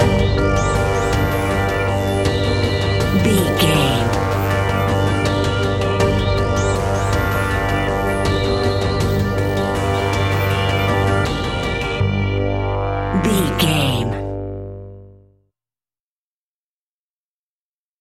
Aeolian/Minor
scary
ominous
dark
haunting
eerie
synthesiser
drums
ticking
suspenseful
electronic music